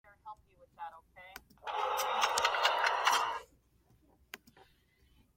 Among Us stab - Sound-Taste
Among Us stab